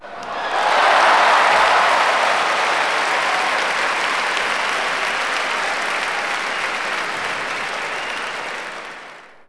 clap_049.wav